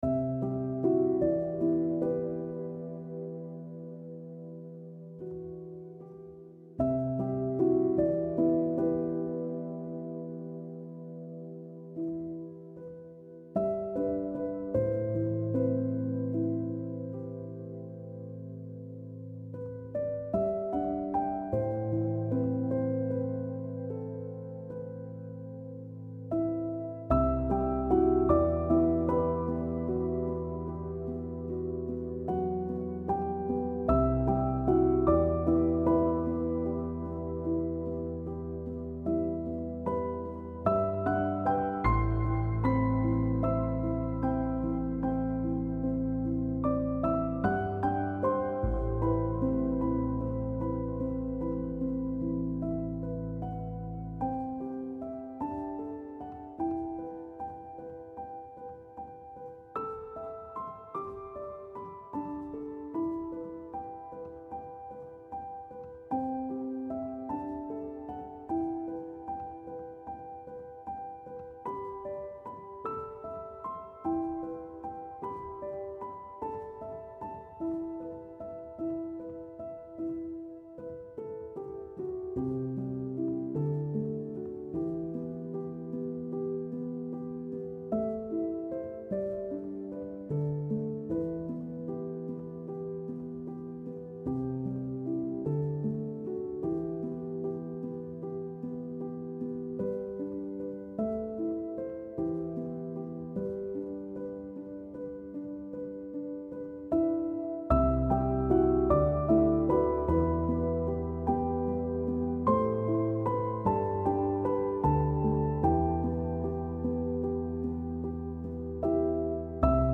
سبک پیانو , مدرن کلاسیک , موسیقی بی کلام